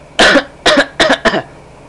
Coughing Sound Effect
Download a high-quality coughing sound effect.
coughing-2.mp3